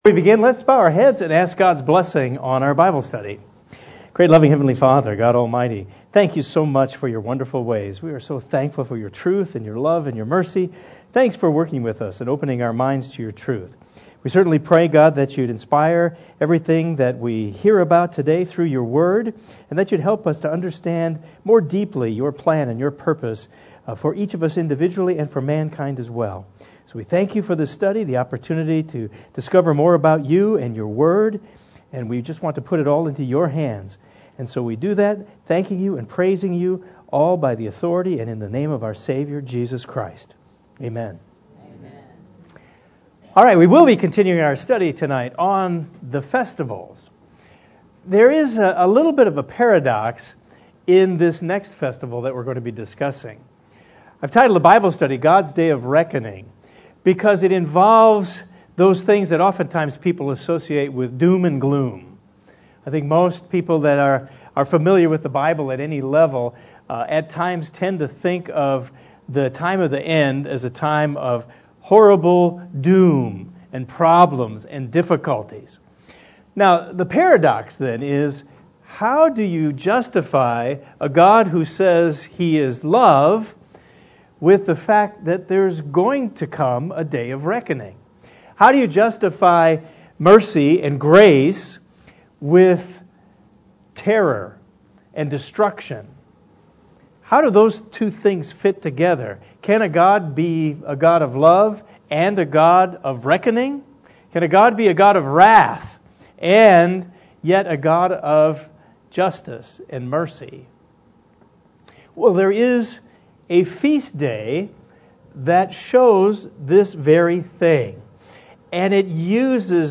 This is part 9 in the Beyond Today Bible study series: Let Us Keep the Feasts.